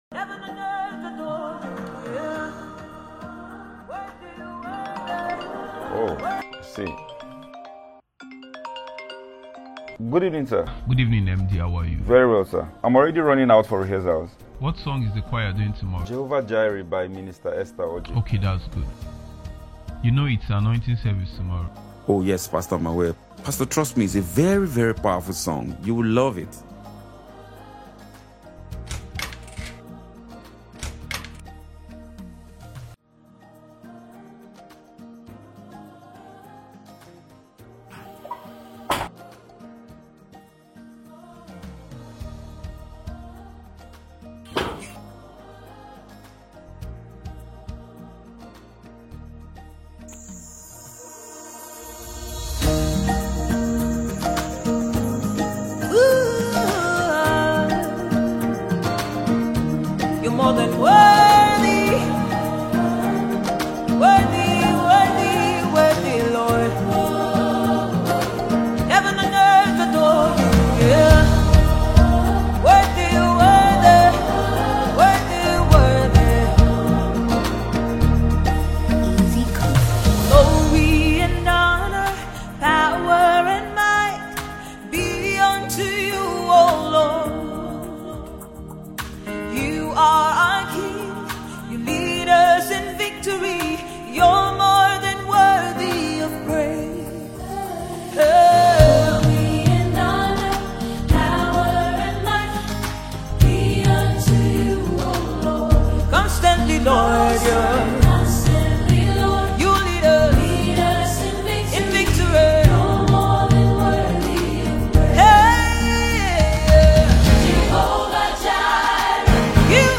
Nigerian gospel
soul-stirring composition
extraordinary vocal range